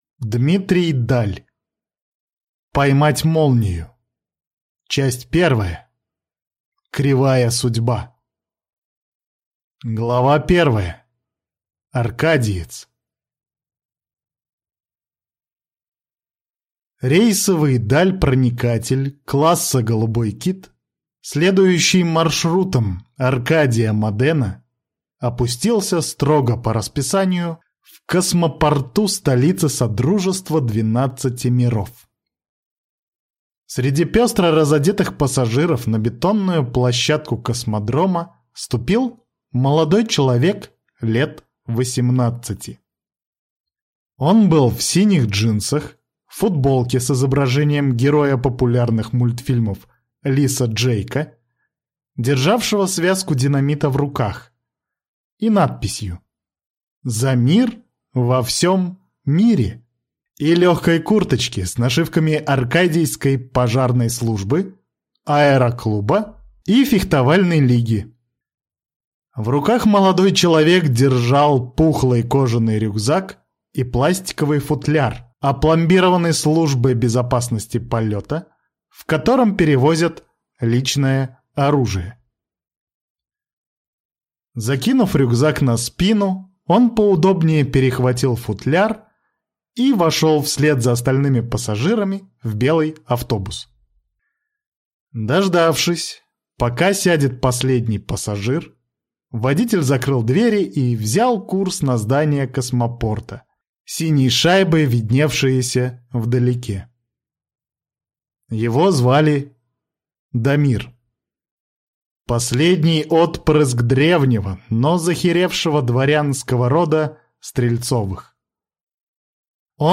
Аудиокнига Поймать молнию | Библиотека аудиокниг